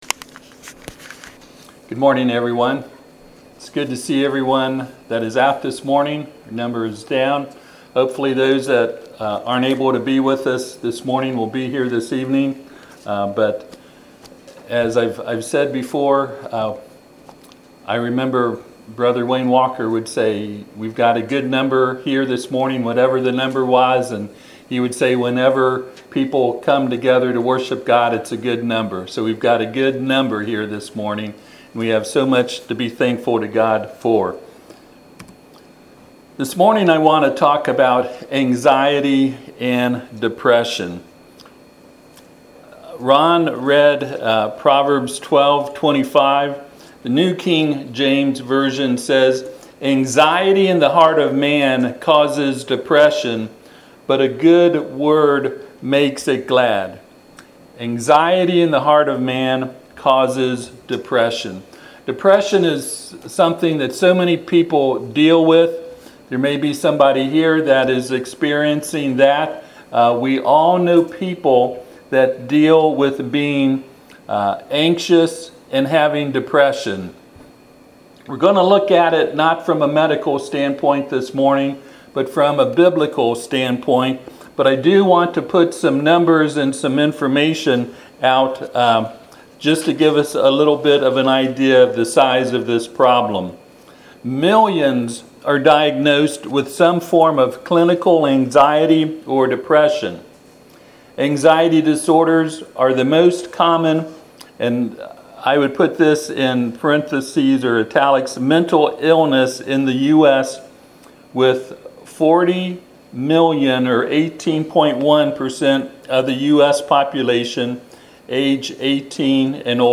Proverbs 12:25 Service Type: Sunday AM Topics: Anxiety , Depression , despair , Hope « Why Are So Many People Not Saved?